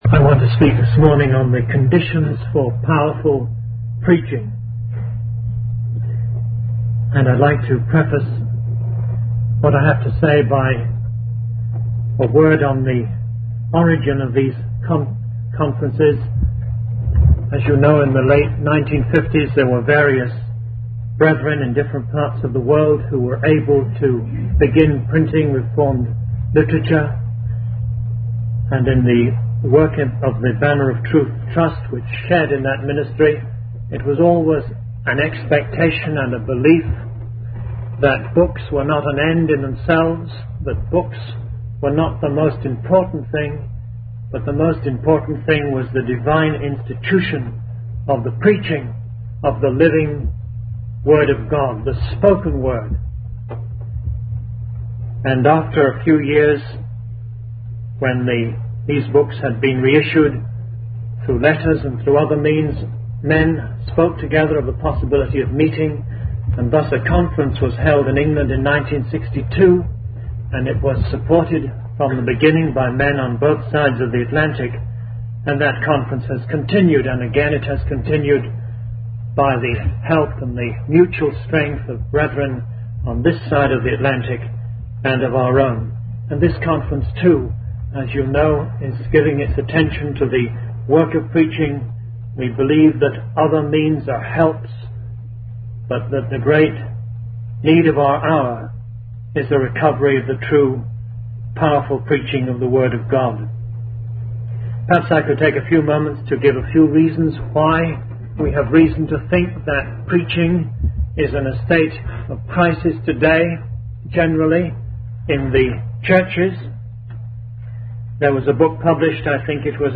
In this sermon, the speaker emphasizes the importance of reading and understanding the word of God.